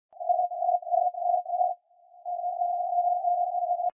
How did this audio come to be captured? My echoes tests (16/09/2005) with about 30w at feed !